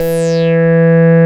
71.01 BASS.wav